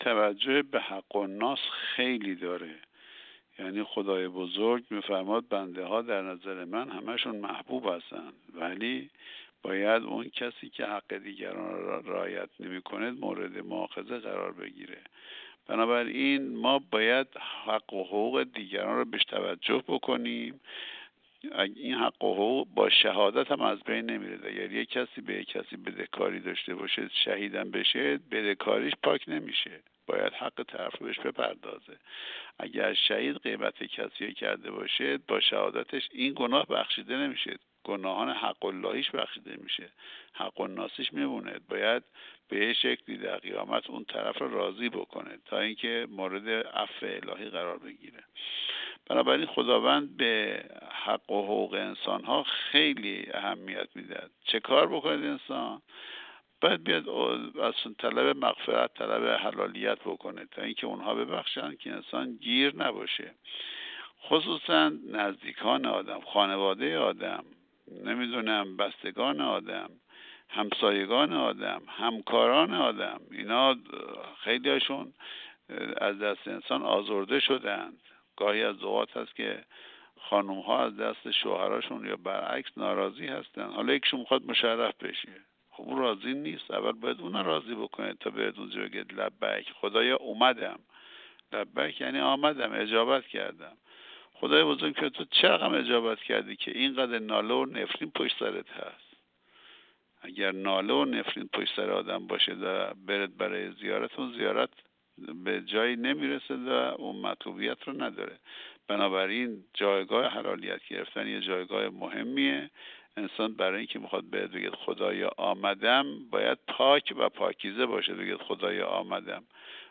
گفتگو با ایکنا